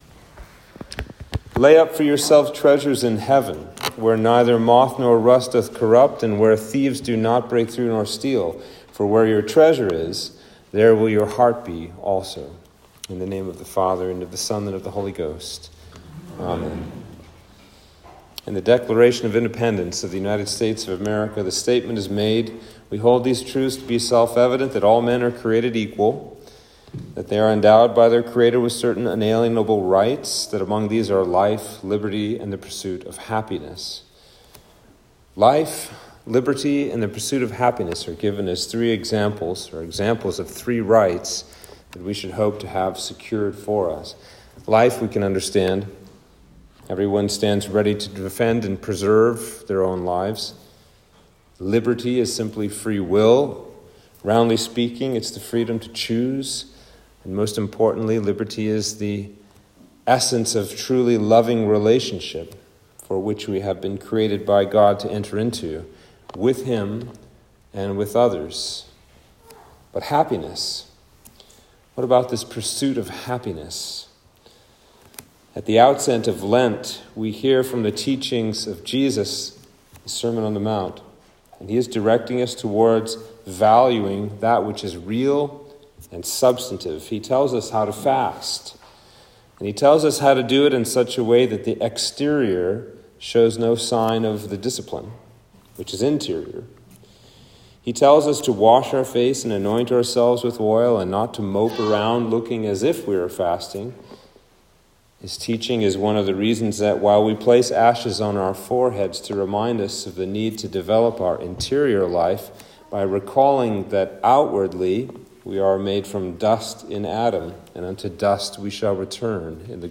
Sermon for Ash Wednesday